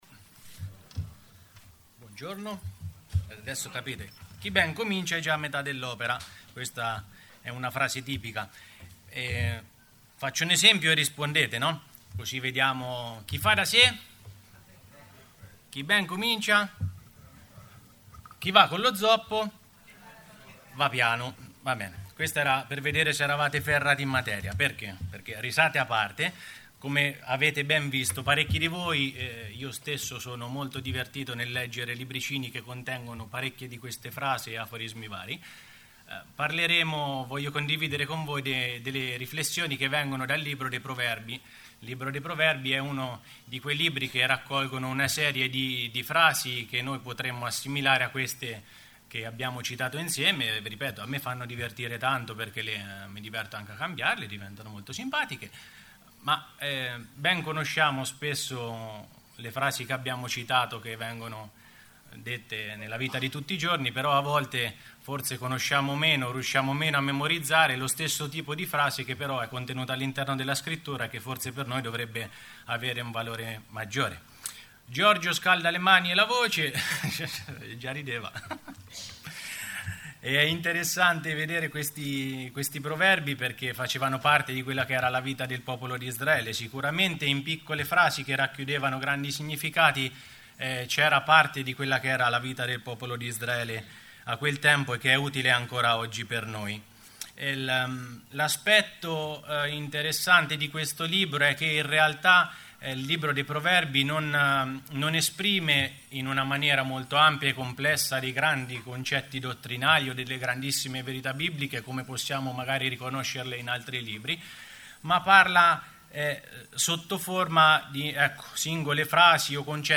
Bible Text: Proverbi 3:1_6 | Preacher
Oratore